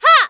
qyh普通4.wav 0:00.00 0:00.30 qyh普通4.wav WAV · 26 KB · 單聲道 (1ch) 下载文件 本站所有音效均采用 CC0 授权 ，可免费用于商业与个人项目，无需署名。
人声采集素材